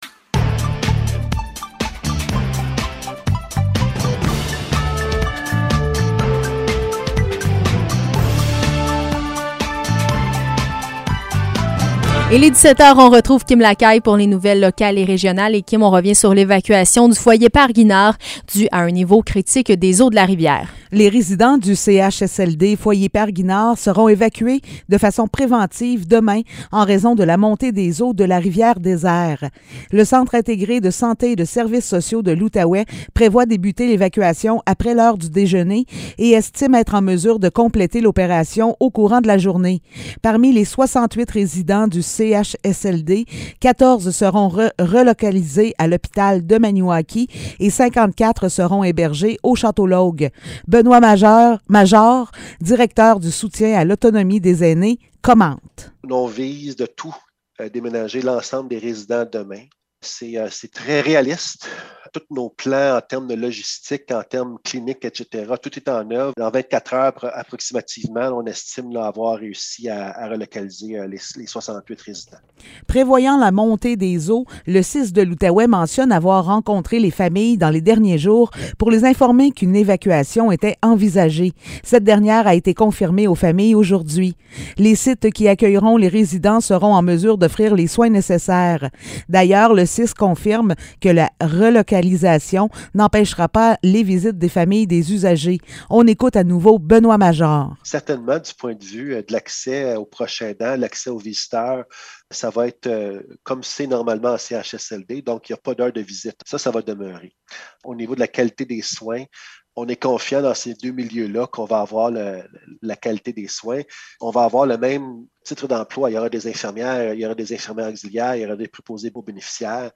Nouvelles locales - 19 mai 2022 - 17 h